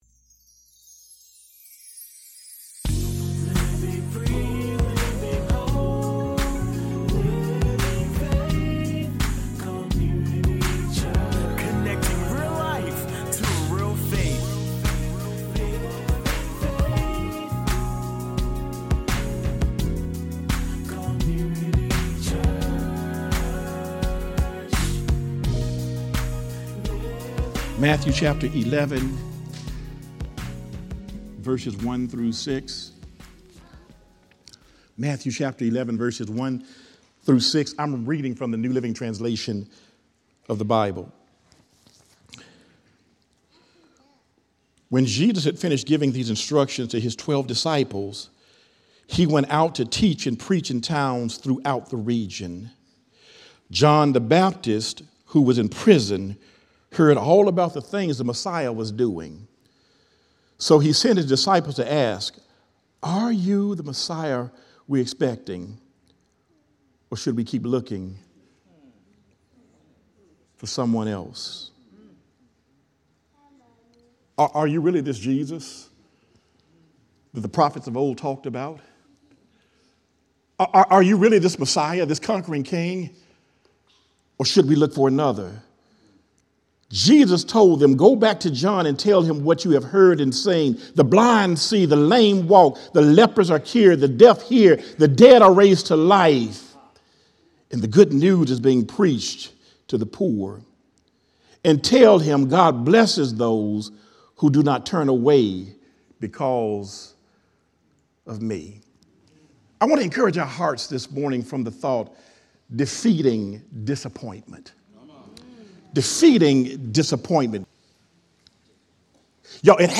6-9-24 “Defeating Disappointment” - Sermon